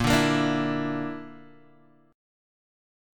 BbMb5 chord